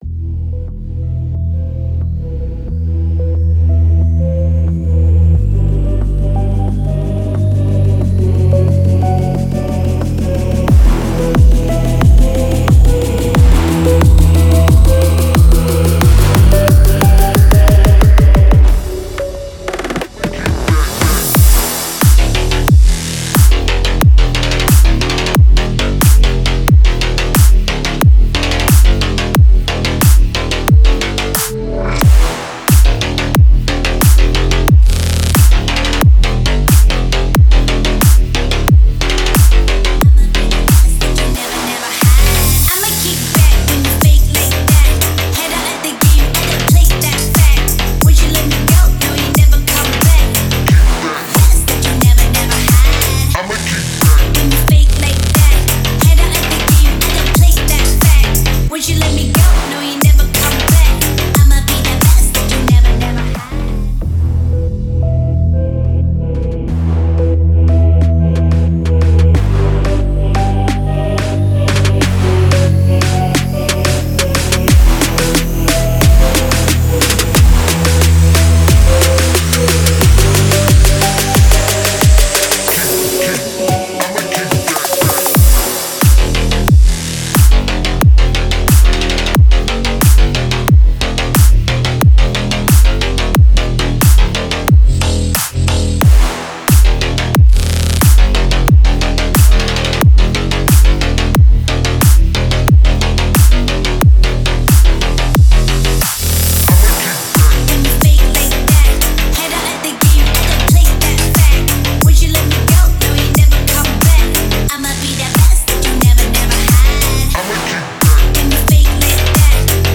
Категория: Клубная музыка
клубные треки